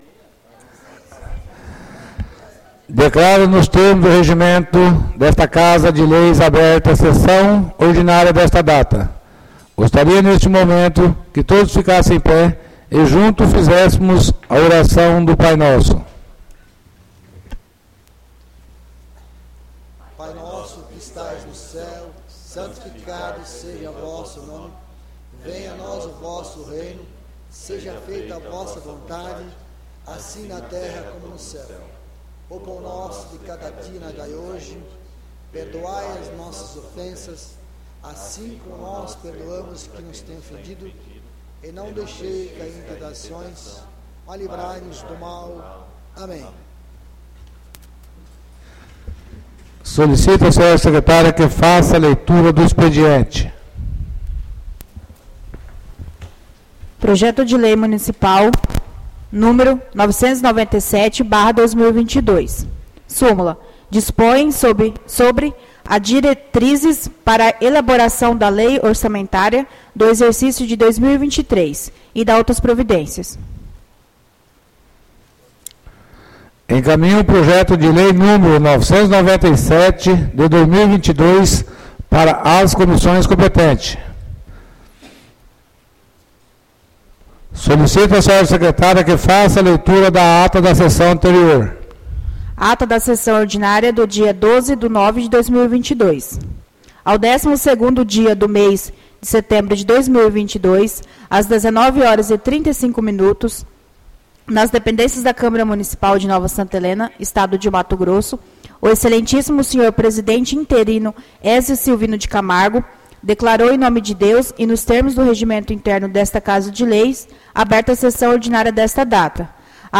ÁUDIO SESSÃO 19-09-22 — CÂMARA MUNICIPAL DE NOVA SANTA HELENA - MT
Sessões Plenárias